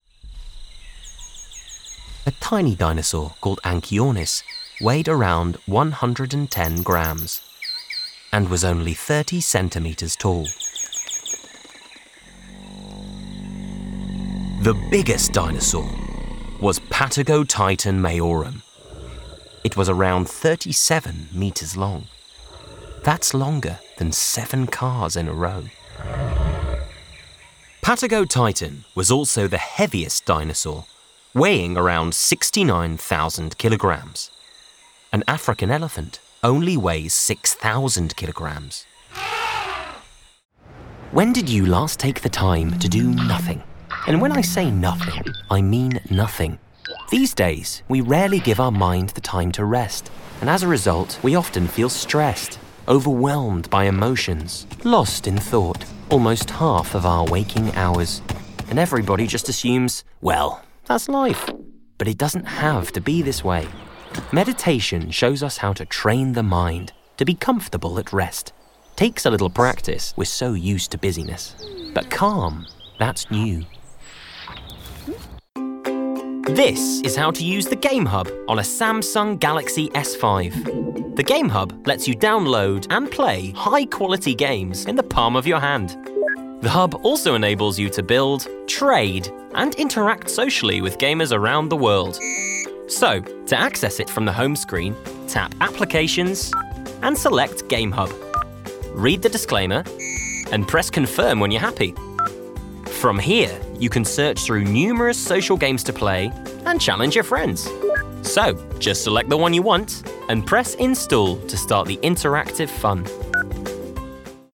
Narration Showreel
Male
Neutral British
Scottish